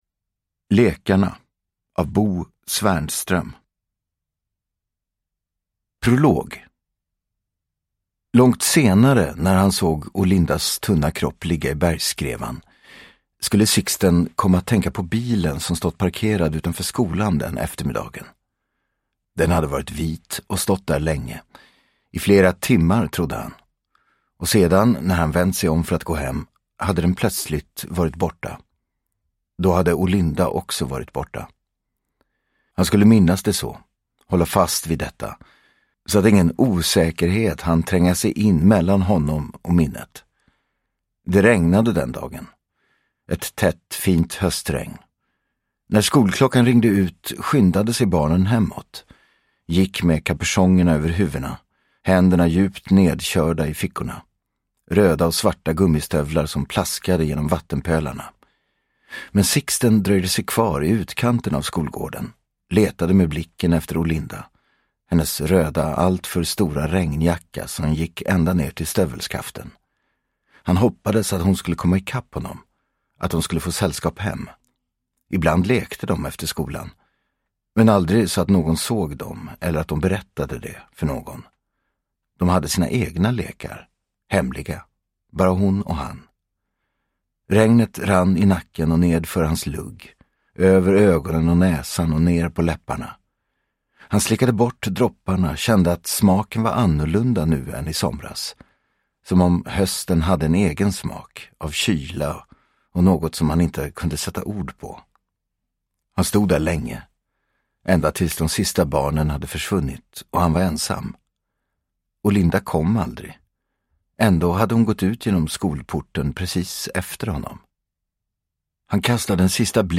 Lekarna – Ljudbok – Laddas ner
Uppläsare: Gerhard Hoberstorfer